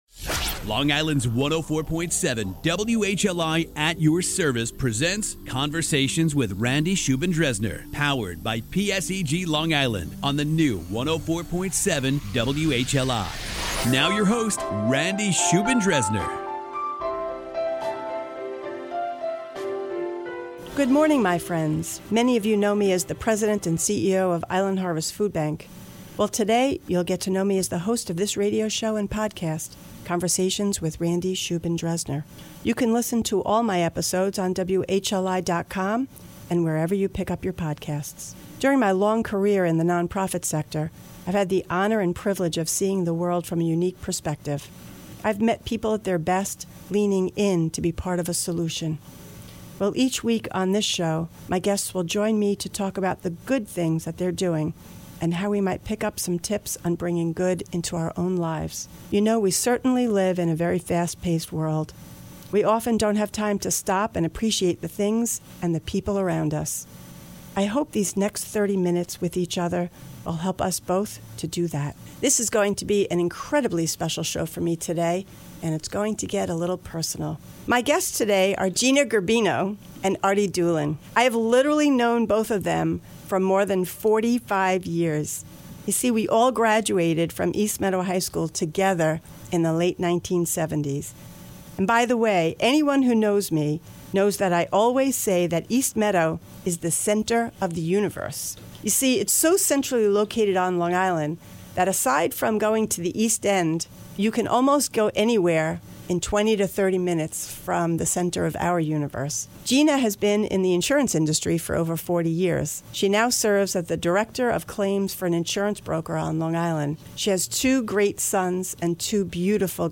Through this lighthearted and fun CONVERSATION, my guests and I debunk the theory that all high school reunions are a potential pain-point and gatherings of people who don’t care about each other.